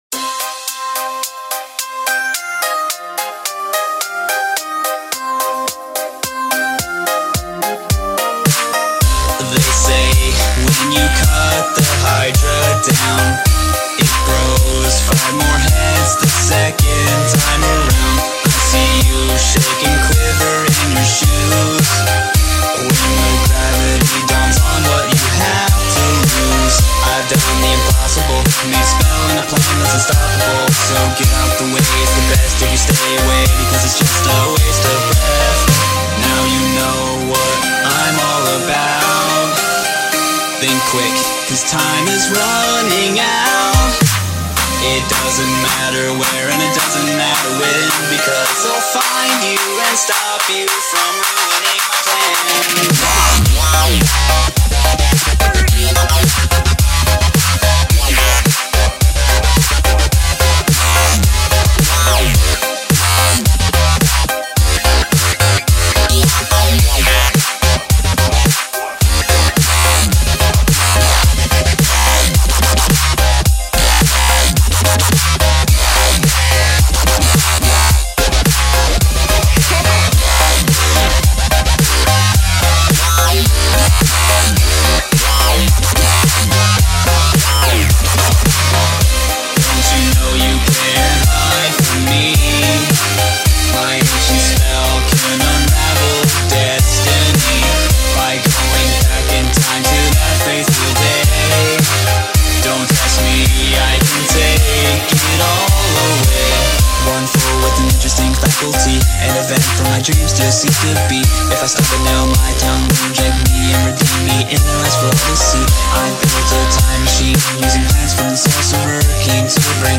BPM - 108 - 150
Glitch Hop ------ Free Download